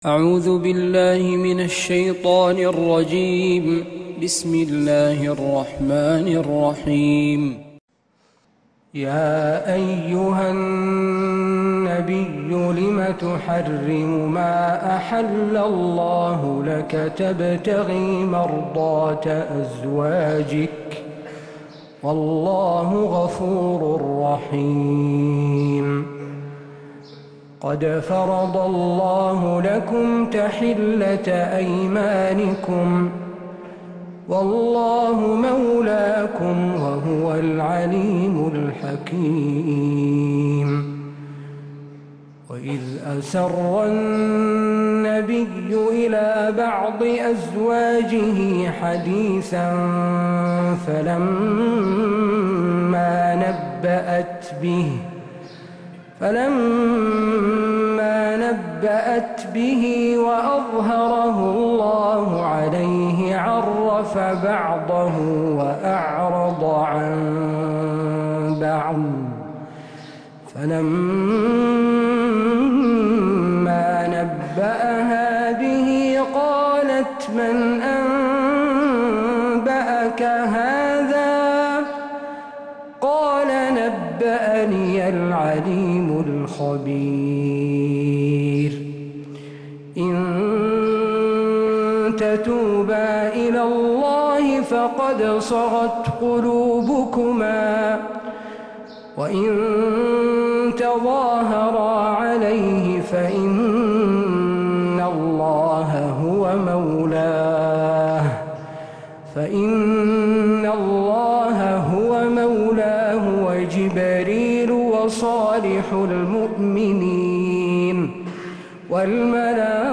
من فجريات الحرم النبوي